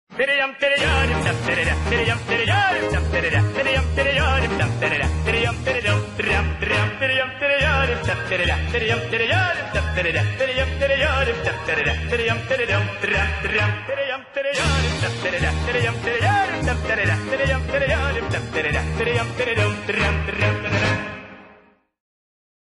Звуки радости
На этой странице собраны звуки радости — от искреннего смеха до бурных оваций.
Звук тириям тириям тириям трам трам тирьям